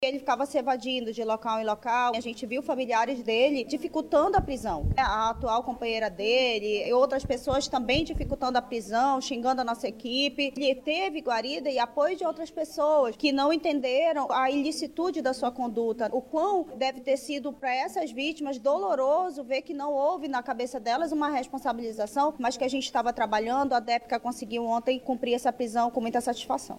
Ainda segundo a delegada, familiares do homem dificultaram o trabalho da Polícia durante o cumprimento do mandado de prisão contra o condenado pela Justiça.